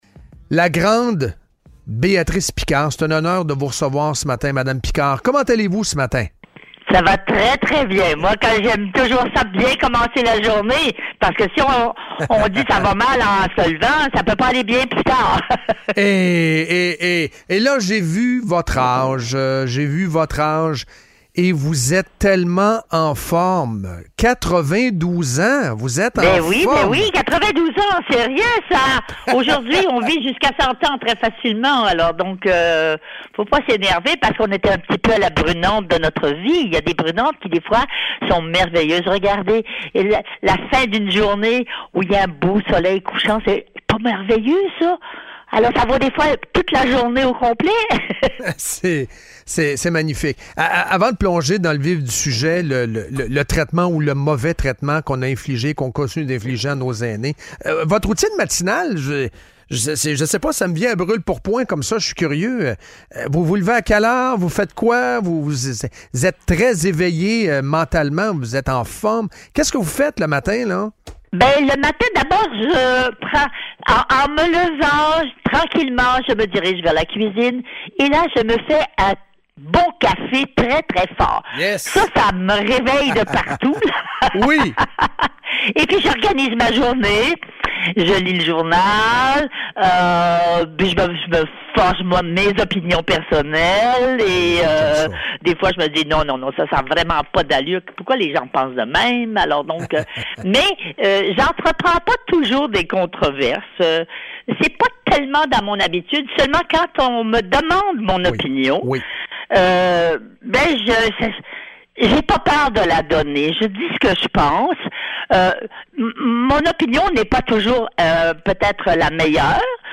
La dernière entrevue de Béatrice Picard dans Maurais Live: Elle critiquait la CAQ et leur TRAITEMENT DES PERSONNES ÂGÉES!